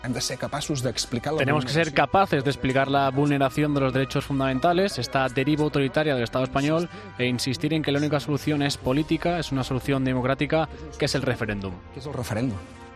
Roger Torrent en una entrevista en TV3